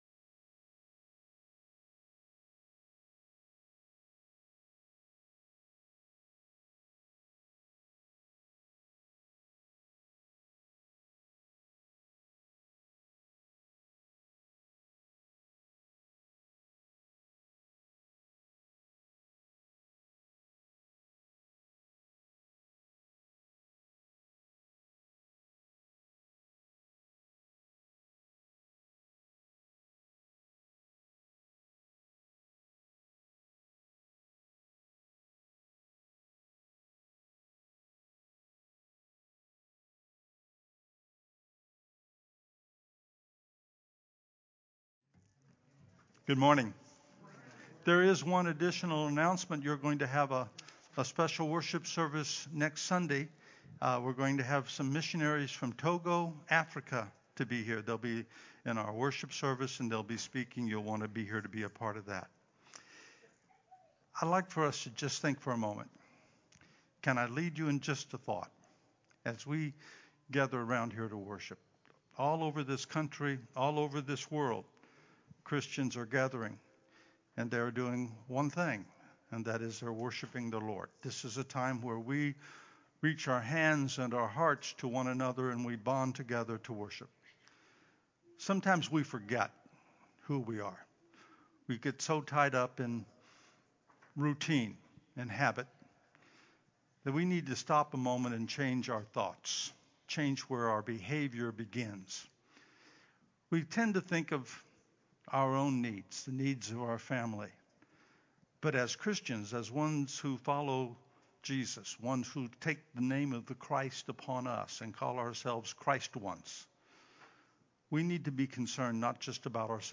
9-25-22 Worship
Praise Worship
Closing Prayer